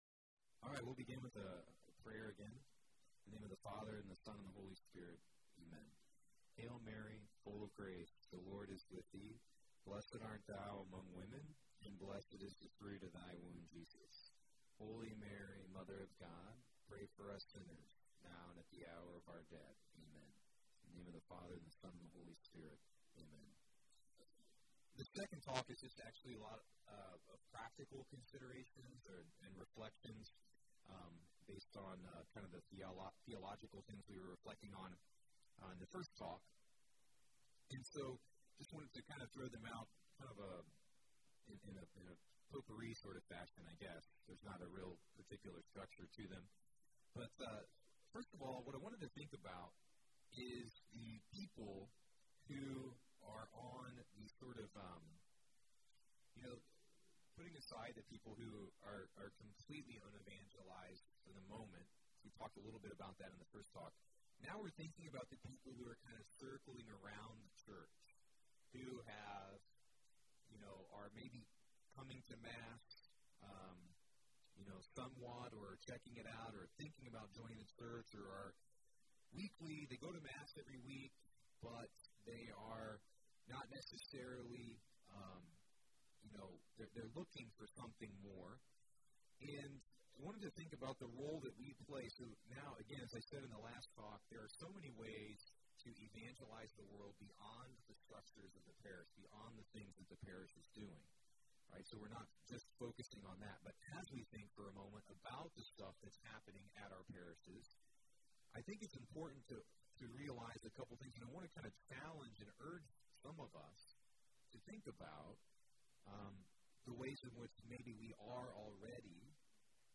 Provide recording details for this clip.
at the Mother of the Redeemer Retreat Center in Bloomington, IN. This one focuses on the practical considerations of the vocation of the laity and their participation in parish life.